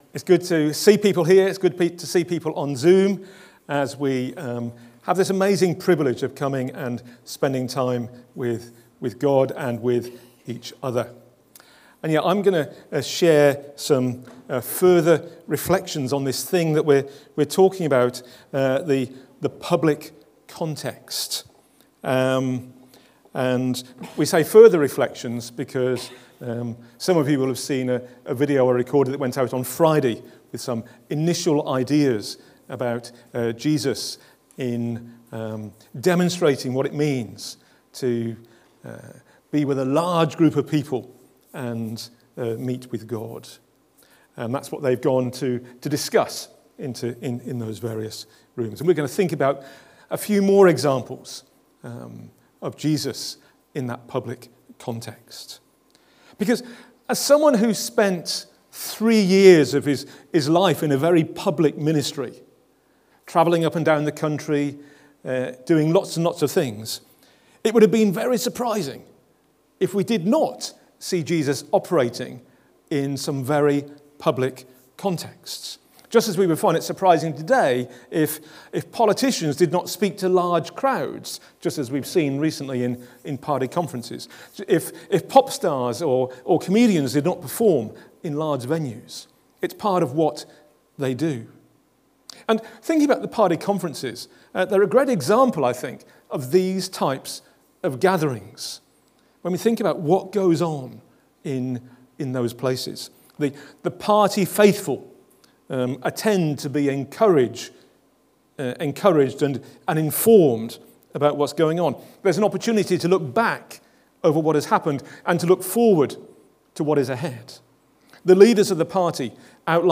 Talks - WSCF